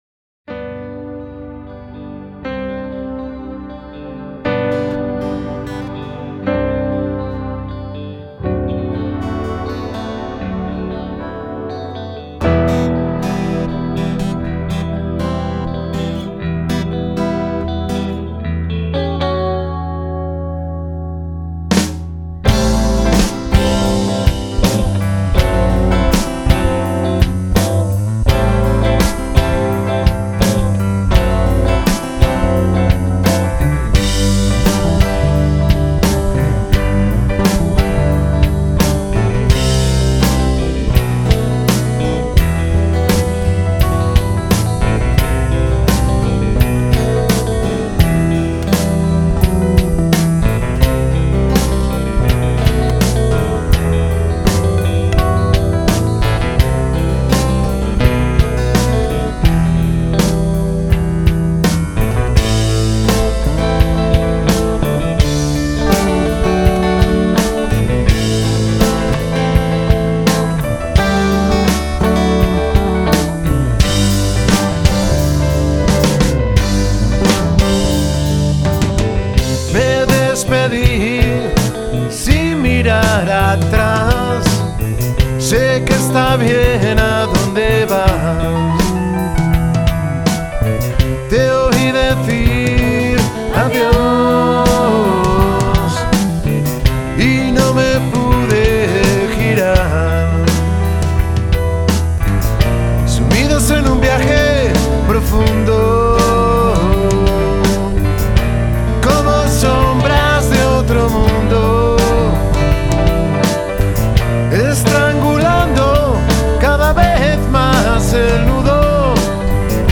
Genero: pop-rock experimental